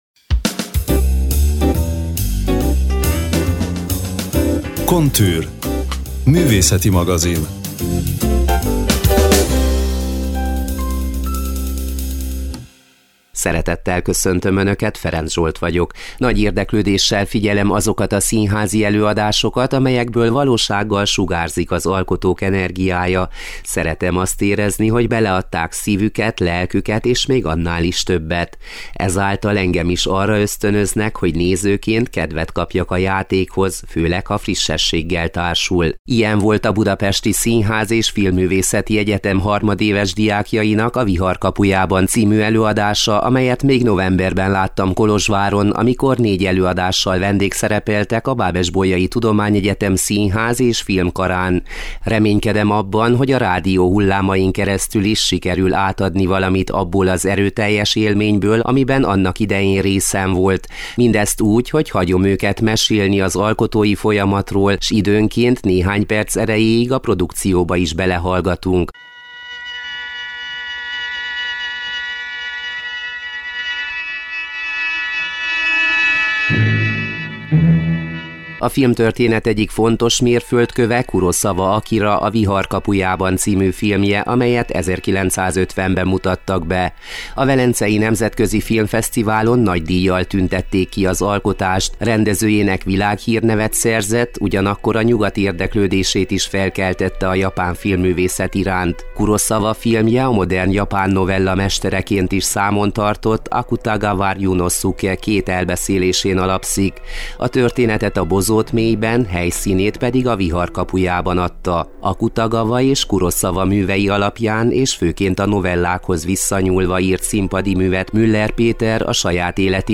Összeállításunk, amelyben a csapat szinte minden tagja megszólal, meghallgatható az alábbi lejátszóra kattintva. https